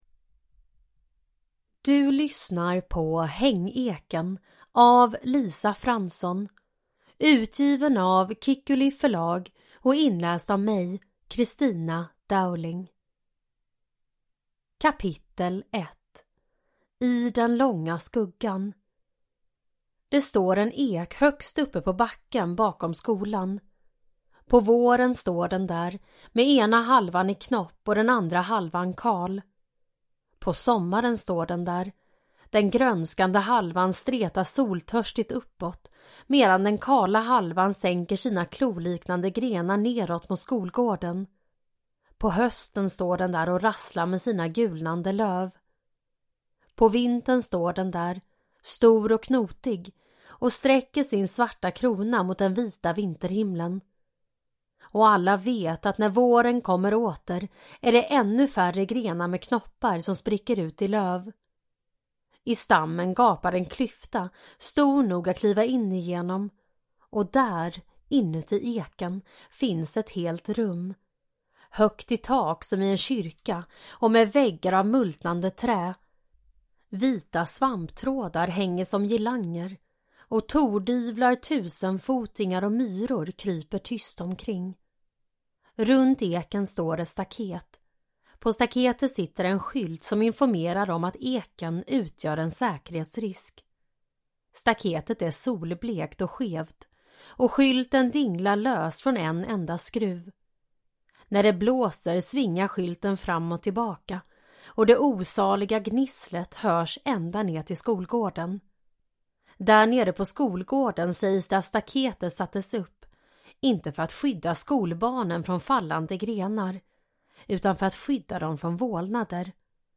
Hängeken – Ljudbok – Laddas ner